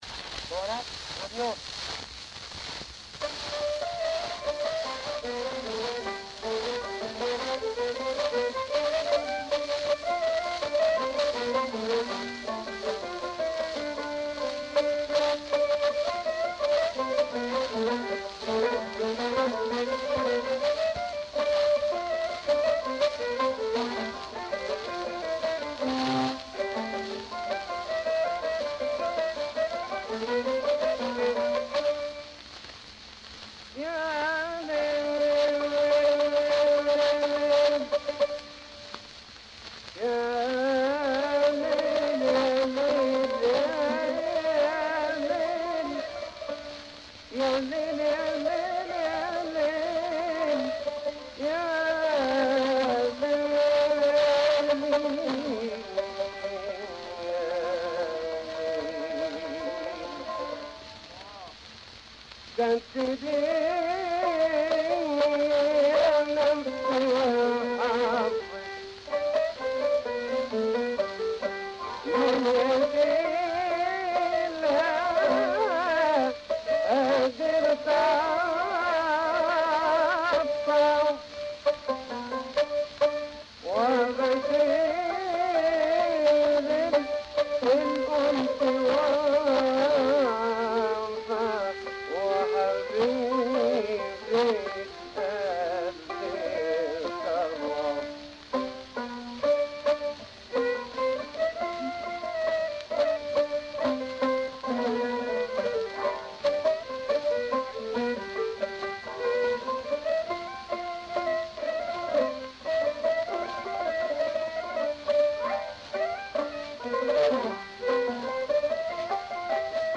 إسم القسم : اغاني مصرية